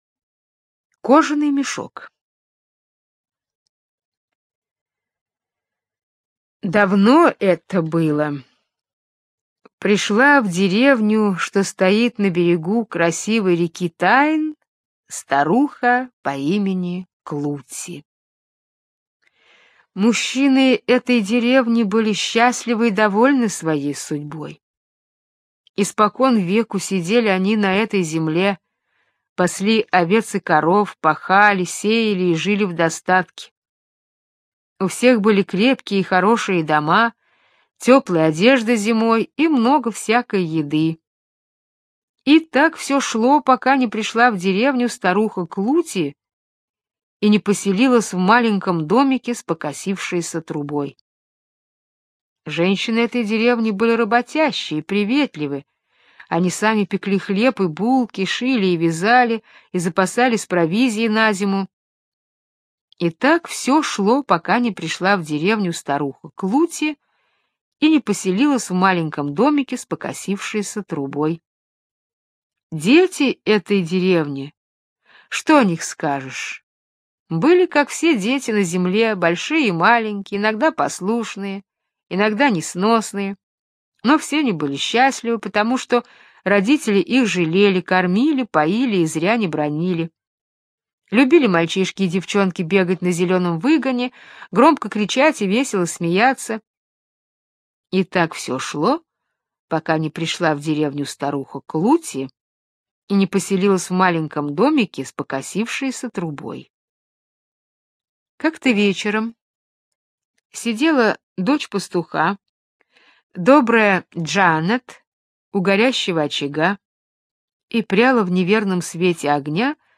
Кожаный мешок - британская аудиосказка - слушать онлайн